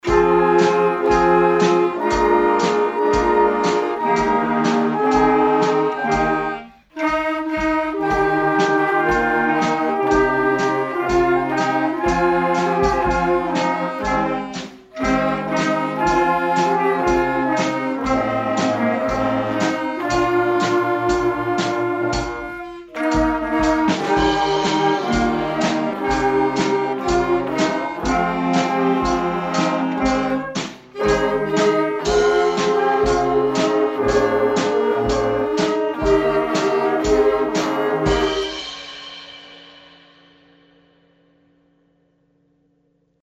Hier finden Sie zwei Hörproben der aktuellen Bläserklasse 2019-21:
In einem solchen Klassenverband lernt jede Schülerin und jeder Schüler ein Blasinstrument.